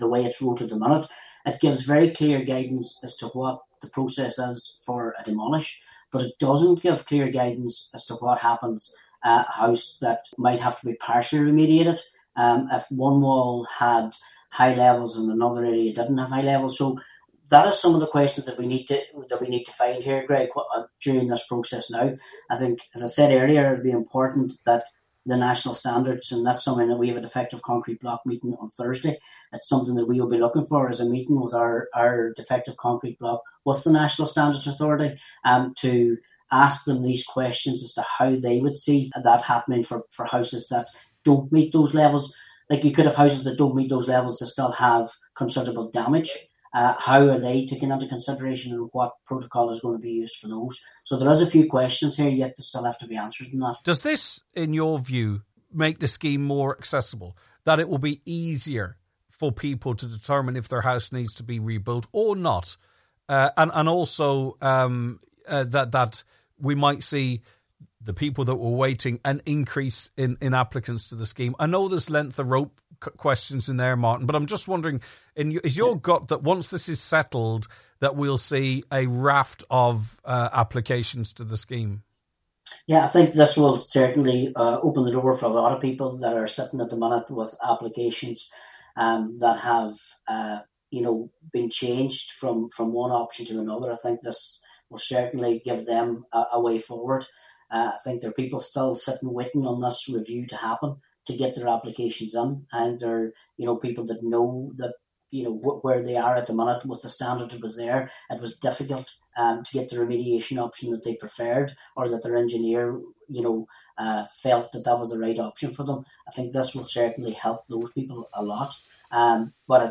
He discussed the issue this morning